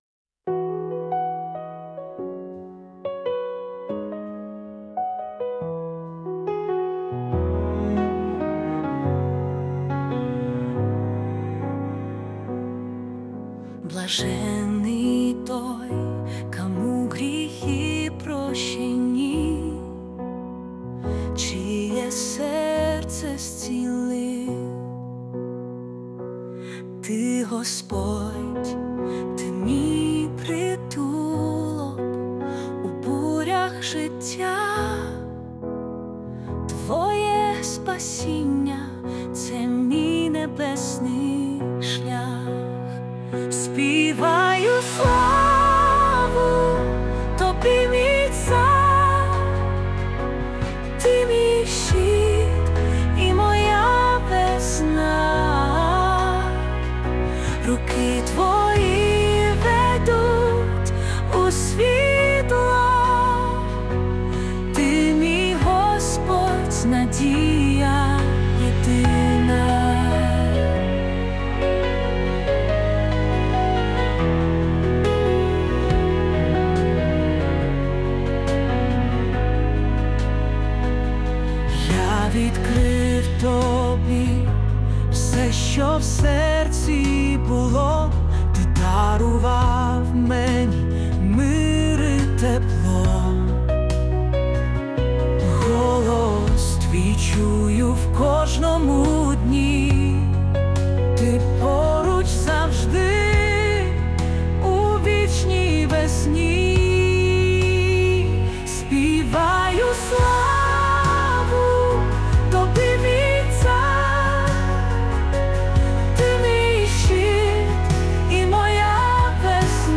песня ai
Jesus Worship